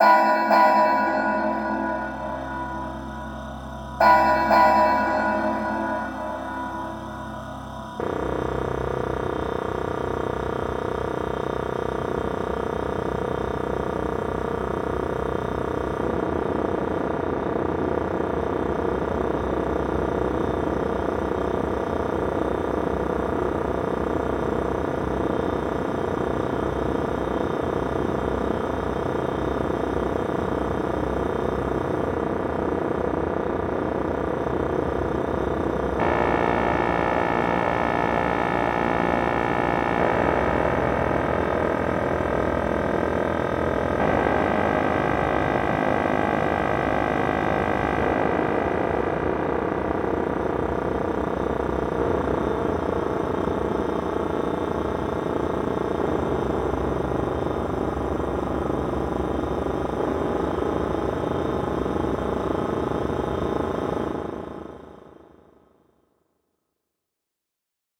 ・BPM：60
・メインの楽器：シンセサイザー音（鐘、アトモスフィア） ・テーマ：洋館、ホラー、不気味、不協和音